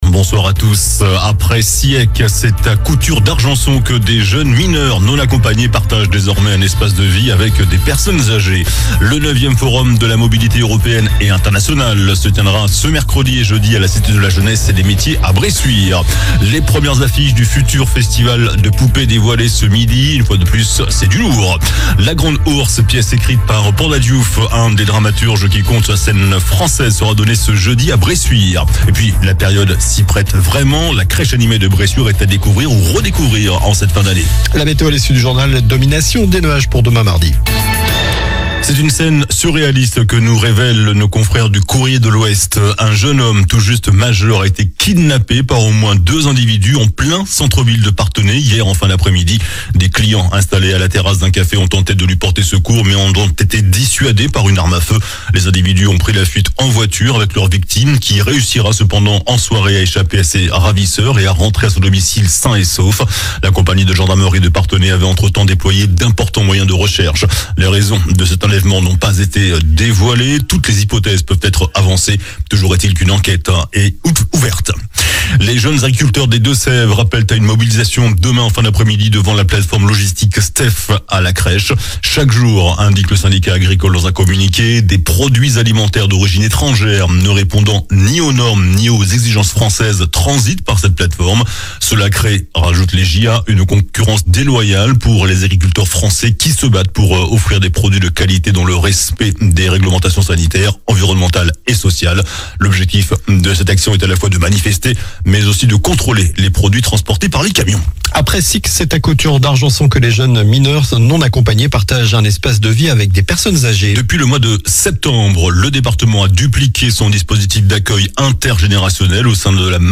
JOURNAL DU LUNDI 25 NOVEMBRE ( SOIR )